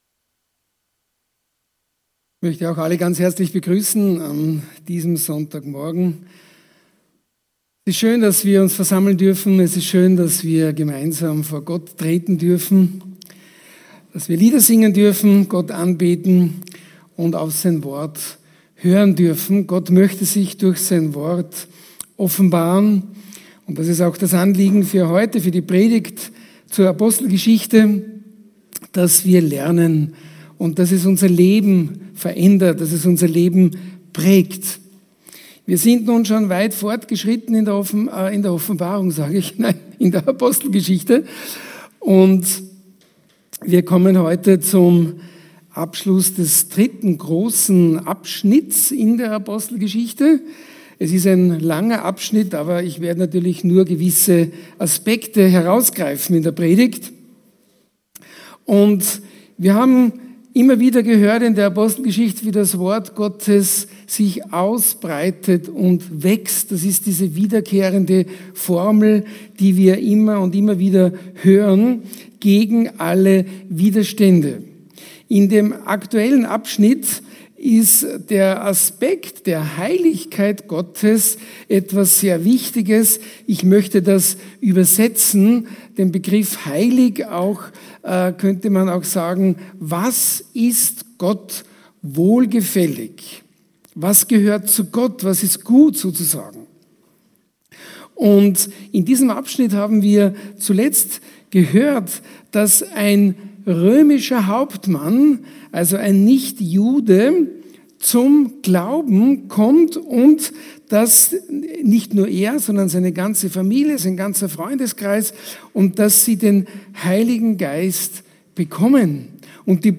Hier finden Sie die aktuellen Sonntagspredigten der Baptistengemeinde Basel.